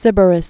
(sĭbər-ĭs)